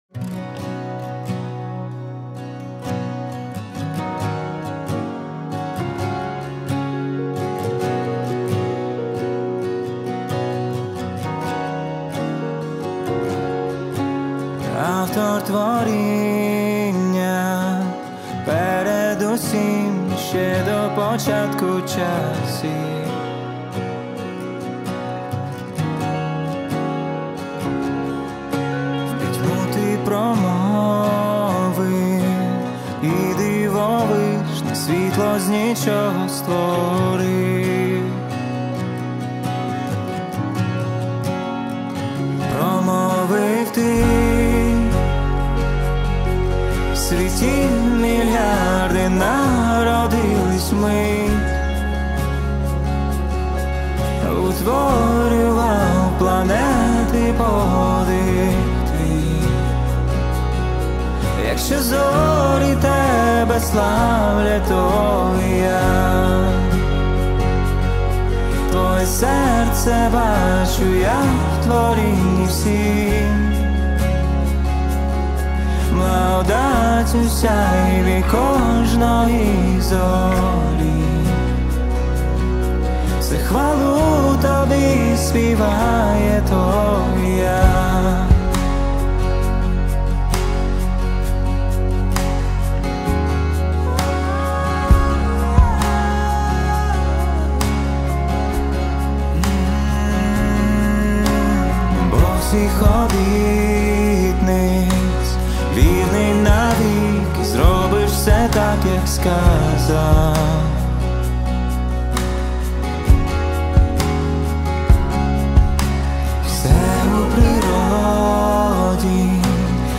184 просмотра 135 прослушиваний 20 скачиваний BPM: 66